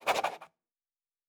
pgs/Assets/Audio/Fantasy Interface Sounds/Writing 1.wav at master
Writing 1.wav